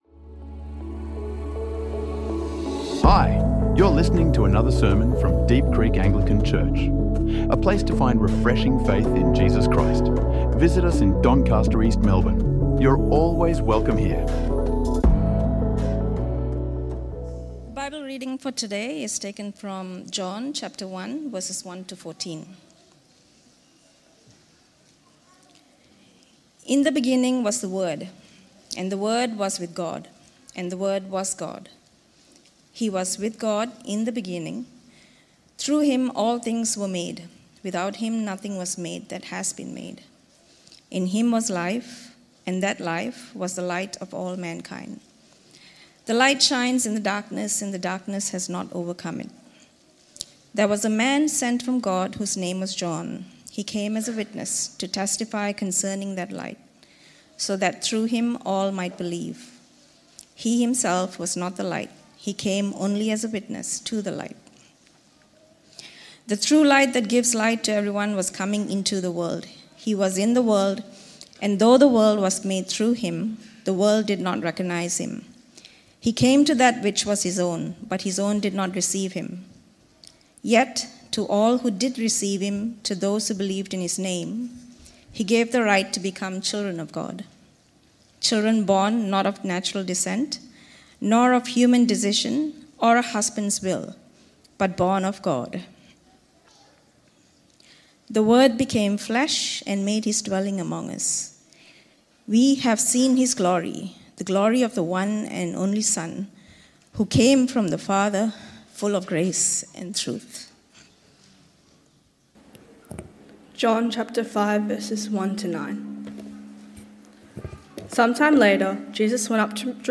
Longing for Glory | Sermons | Deep Creek Anglican Church